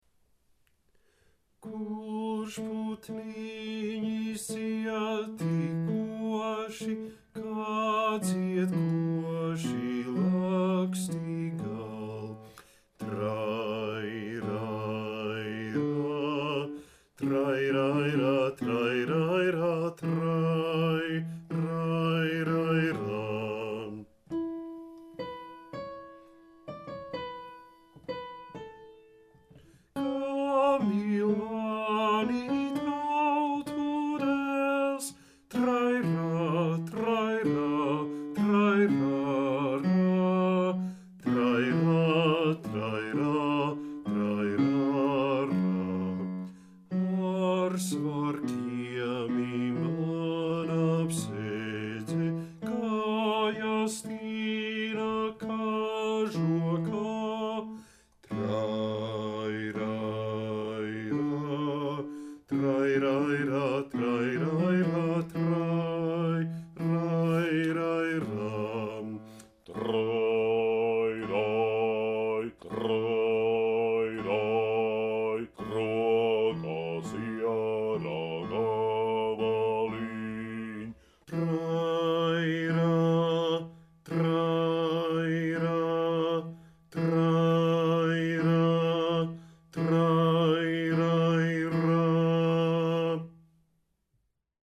Baritons